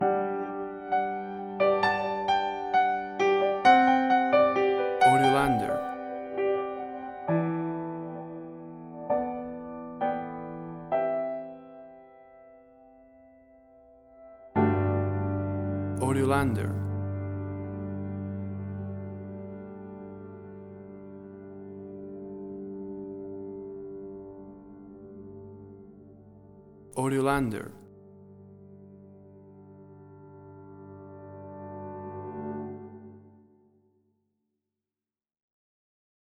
WAV Sample Rate: 16-Bit stereo, 44.1 kHz
Tempo (BPM): 65